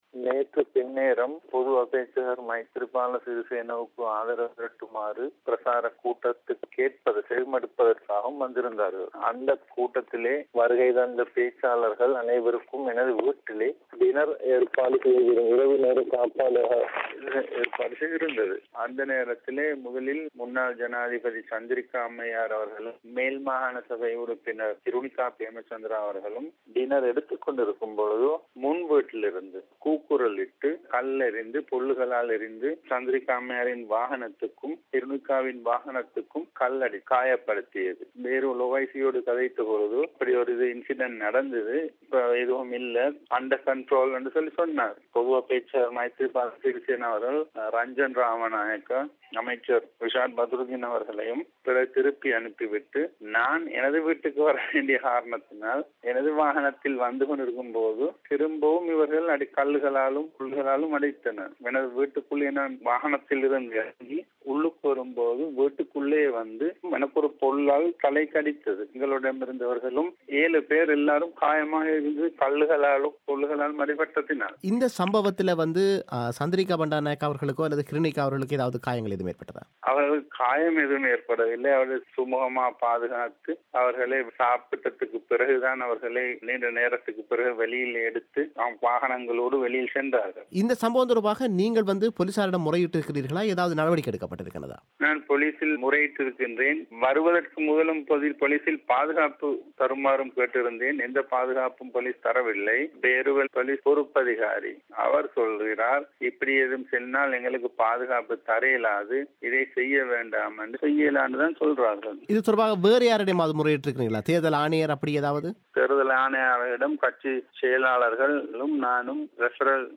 அவரது செவ்வியை நேயர்கள் இங்கே கேட்கலாம்.